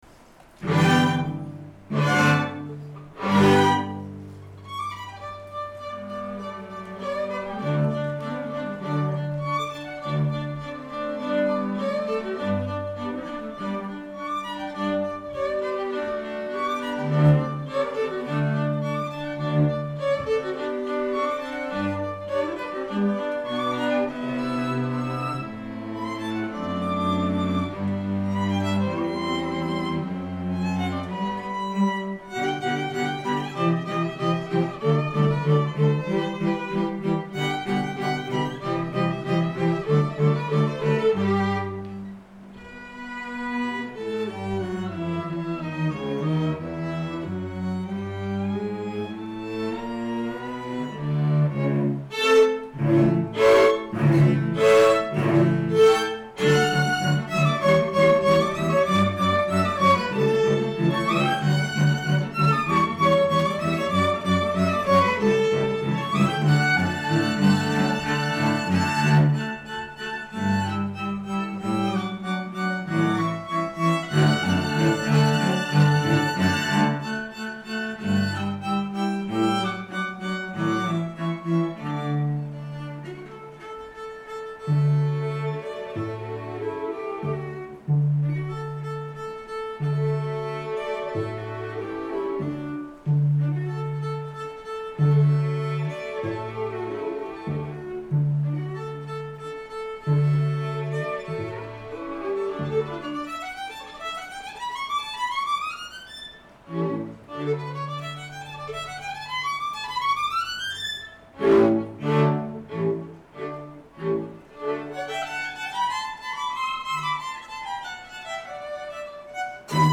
2:00 PM on July 28, 2012, South Bay Church
Dittersdorf Cello Quintet
Allegro